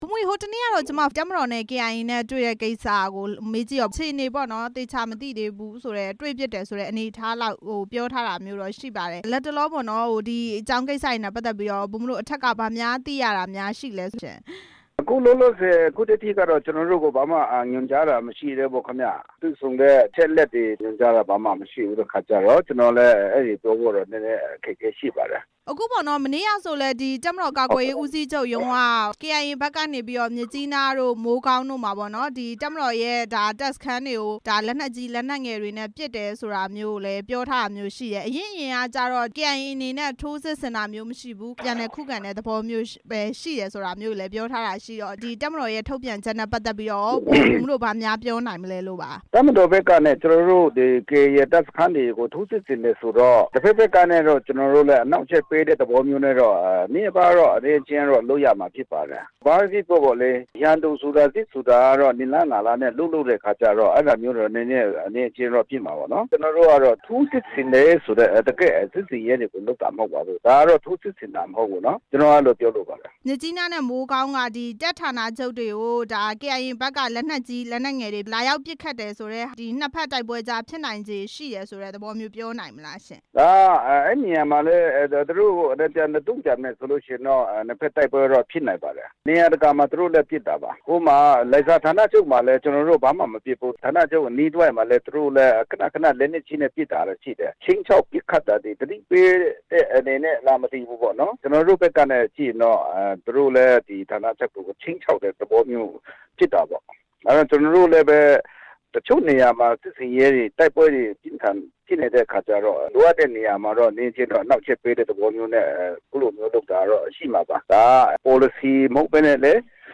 မြစ်ကြီးနားနဲ့ မိုးကောင်းမြို့နယ်က စစ်တပ်စခန်းတွေကို တိုက်ခိုက်မှု မေးမြန်းချက်